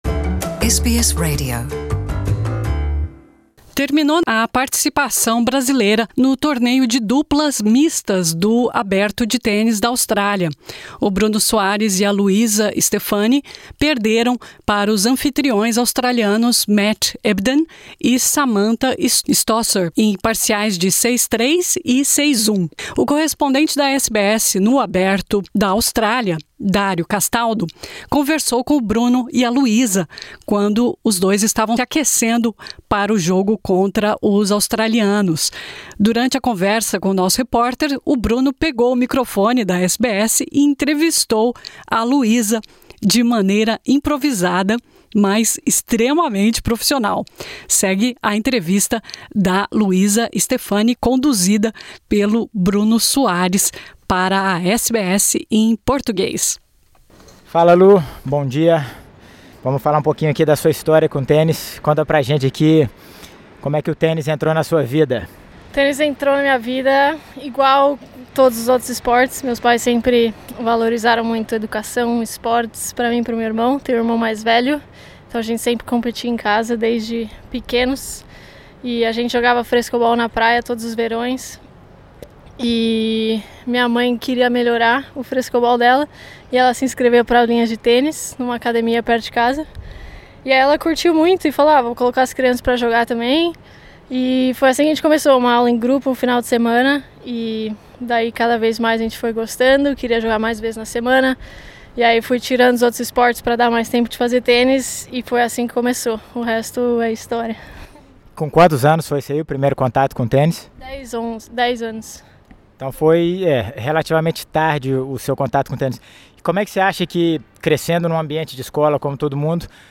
Bruno Soares, atualmente o quinto melhor do mundo no ranking da ATP de duplas, entrevistou Luisa Stefani, a 31º no ranking da ATP, durante um intervalo de treino no Aberto da Austrália, em Melbourne.
Bruno pegou o microfone da SBS e entrevistou Luisa Stefani, de maneira improvisada, mas extremamente profissional.